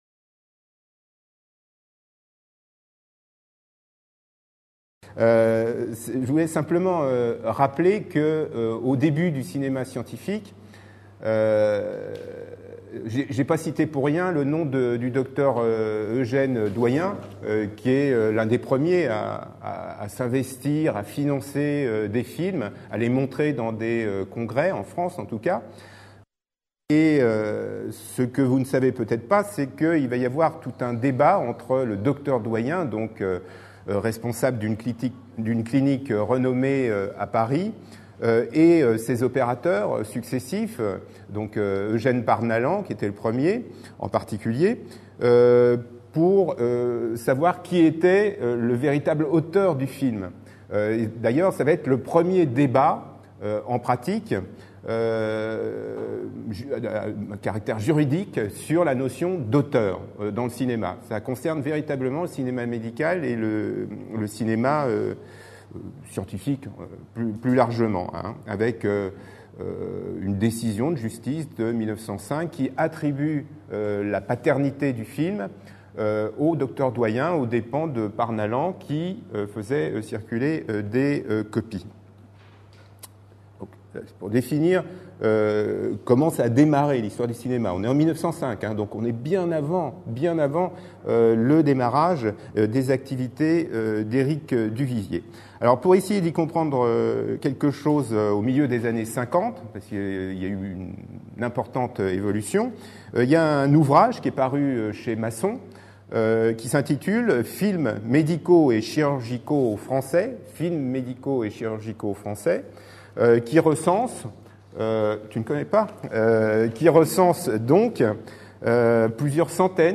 MEDFILM Journée d’étude n°2 21 septembre 2012 à l'Université Paris Diderot Grands Moulins